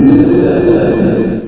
Wingnuts / SFX
Amiga 8-bit Sampled Voice
KillerPigeon.mp3